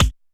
Kik Fatrok 01.wav